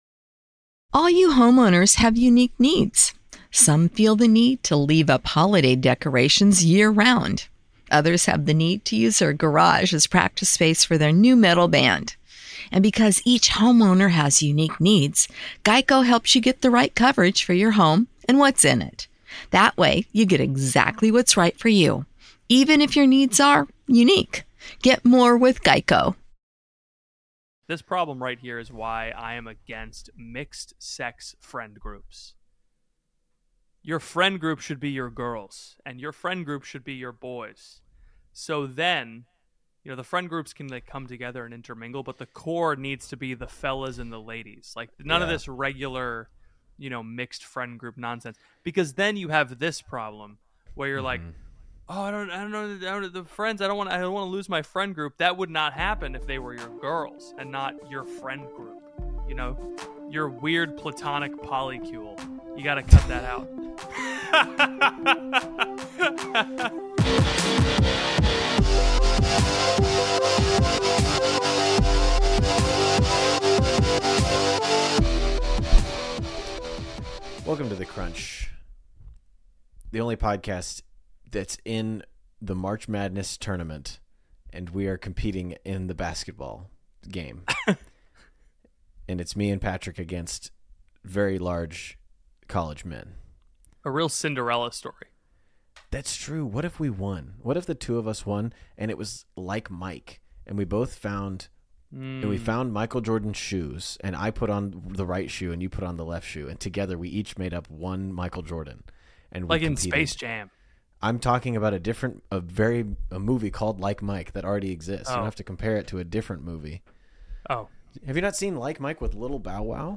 A Comedy Podcast for Young Catholics.